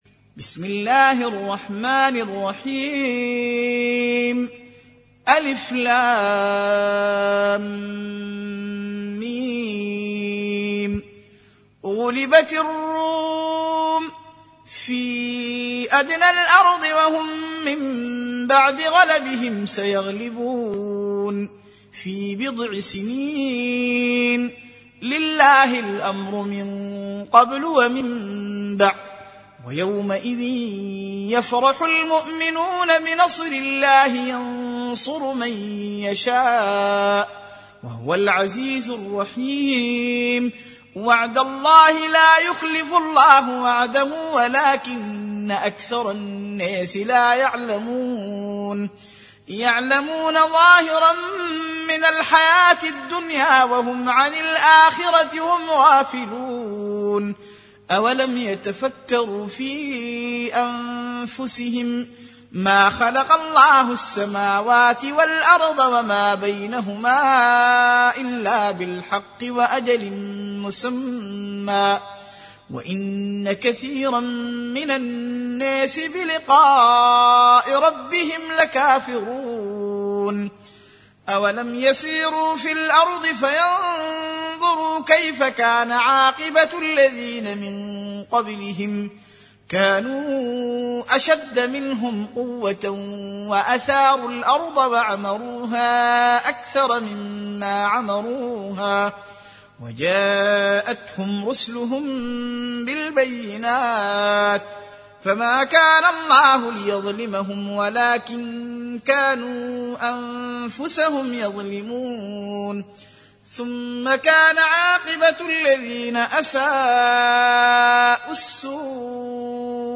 Surah Repeating تكرار السورة Download Surah حمّل السورة Reciting Murattalah Audio for 30. Surah Ar�R�m سورة الرّوم N.B *Surah Includes Al-Basmalah Reciters Sequents تتابع التلاوات Reciters Repeats تكرار التلاوات